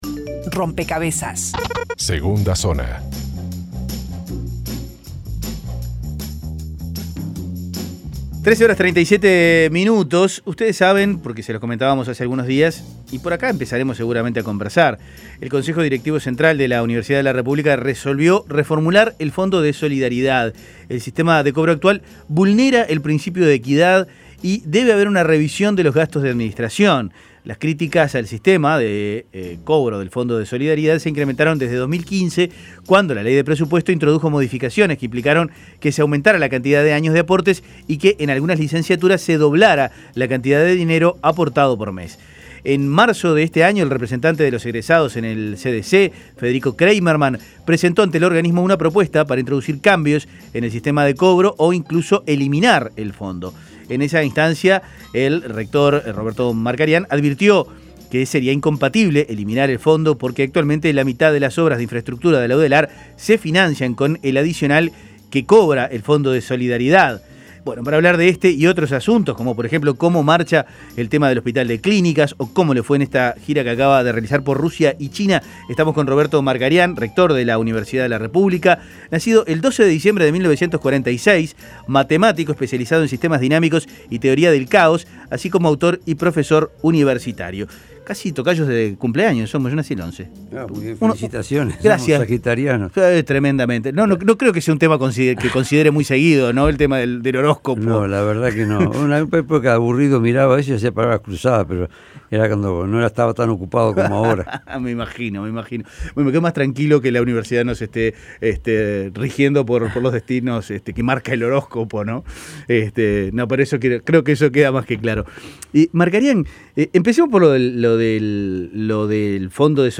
Entrevista en Rompkbzas Buscan cambiarle el chip a la Udelar El rector de la Universidad de la Rep�blica, Roberto Markarian.